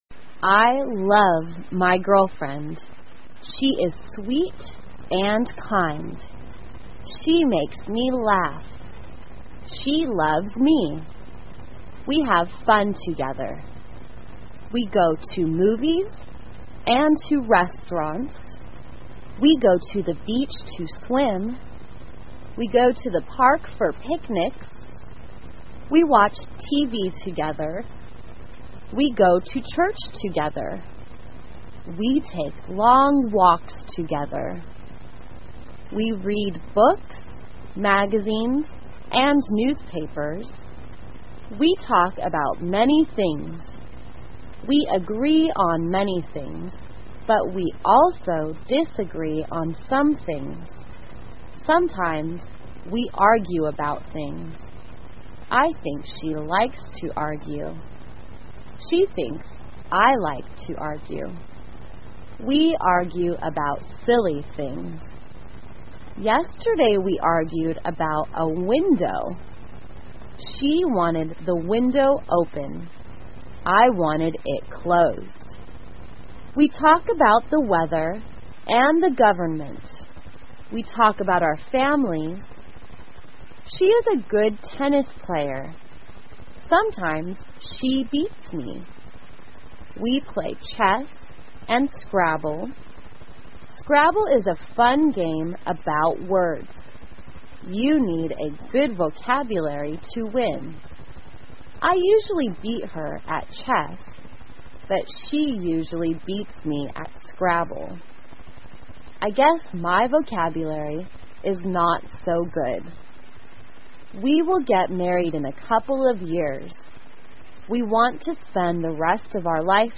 慢速英语短文听力 我的女朋友 听力文件下载—在线英语听力室